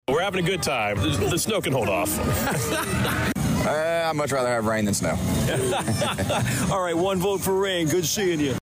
AUDIO: (Downtown Danville Parade of Lights attendees calling for rain.)